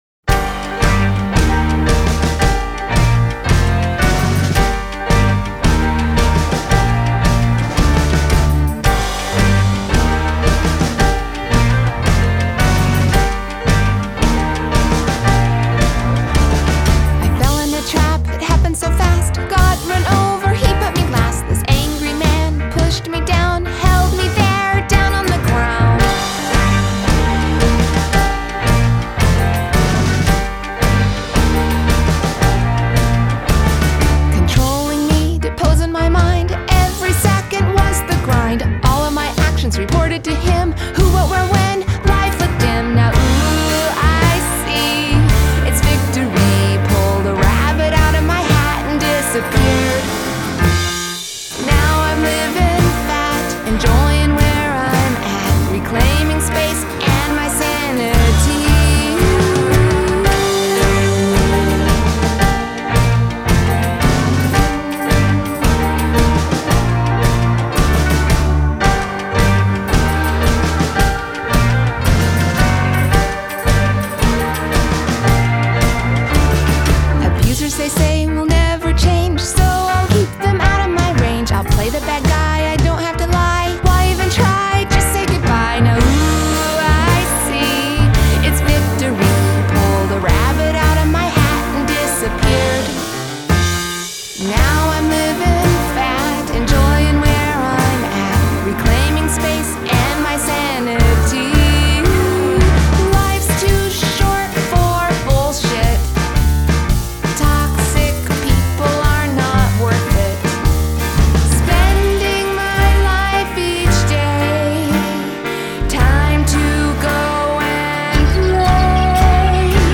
Adult Contemporary
Indie Pop , Musical Theatre